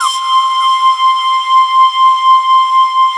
SYNPIPE C5-L.wav